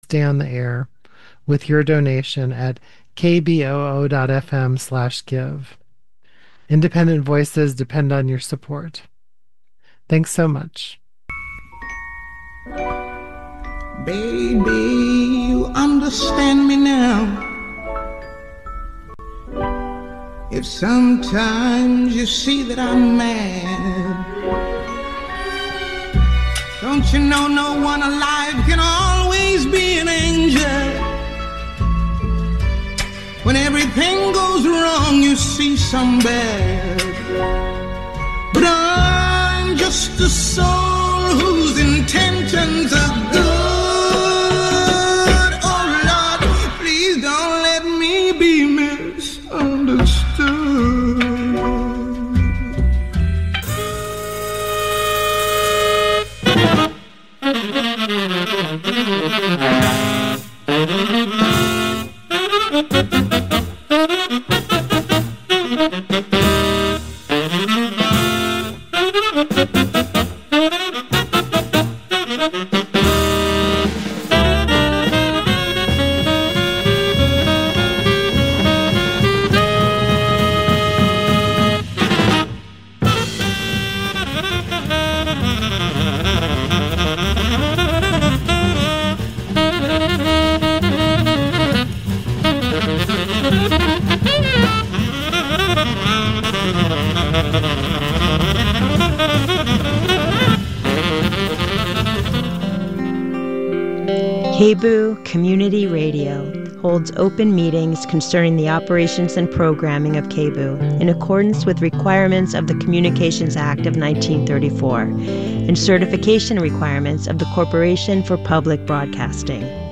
Daily Hip Hop Talk Show